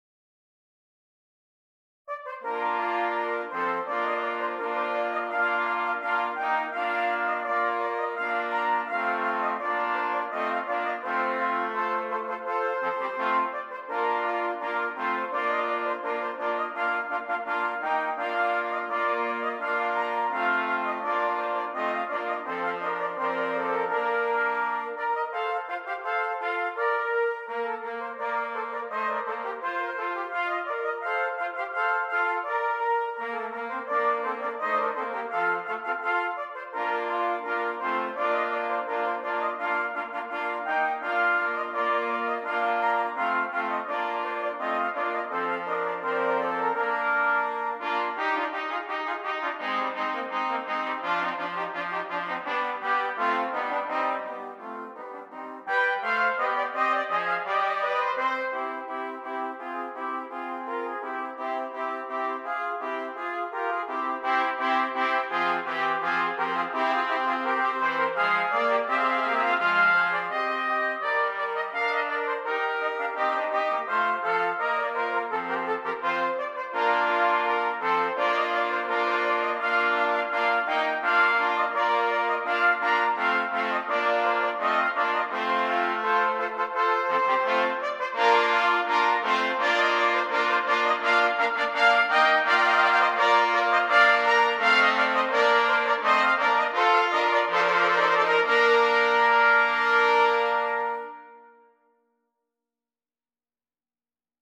Brass Band
4 Trumpets